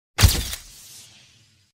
Download Free Camera Flash Sound Effects
Camera Flash